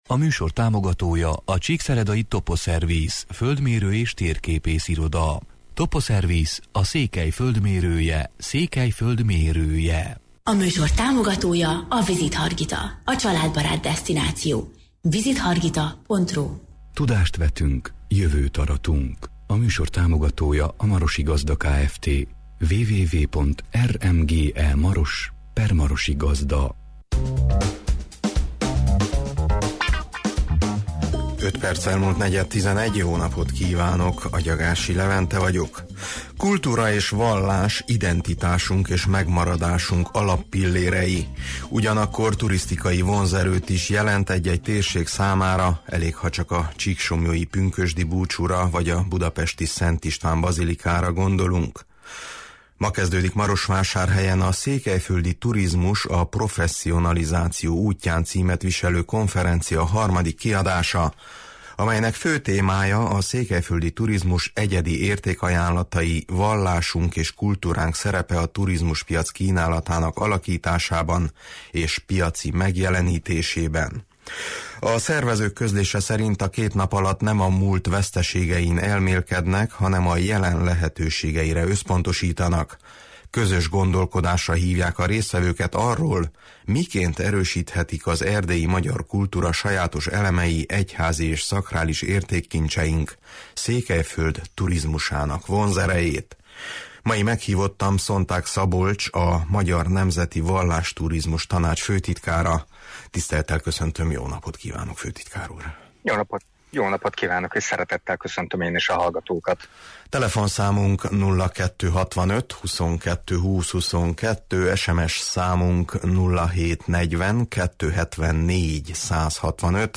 Mai meghívottam